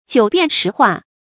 發音讀音
ㄐㄧㄨˇ ㄅㄧㄢˋ ㄕㄧˊ ㄏㄨㄚˋ
jiǔ biàn shí huà